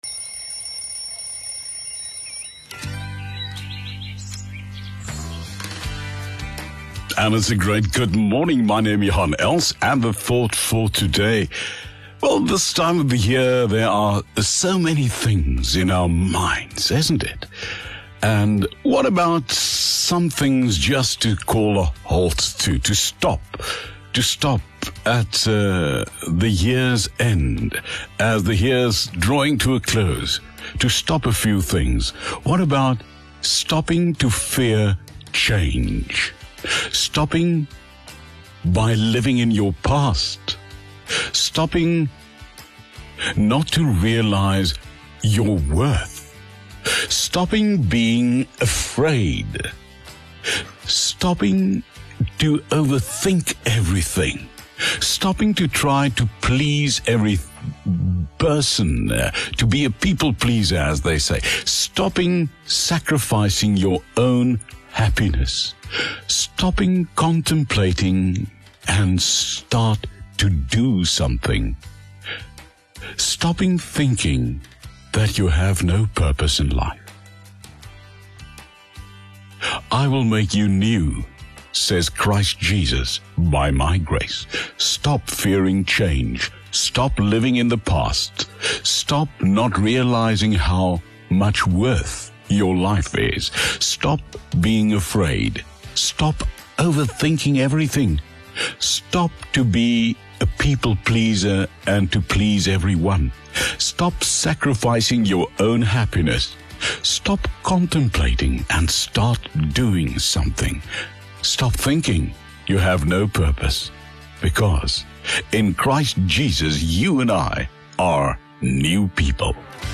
Thought for the Day as heard on OFM on 21 December 2021.